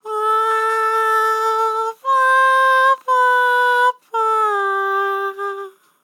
MOUTH BRASS2 Sample
Categories: Vocals Tags: BRASS2, dry, english, fill, LOFI VIBES, male, MOUTH, sample
MAN-LYRICS-FILLS-120bpm-Am-17.wav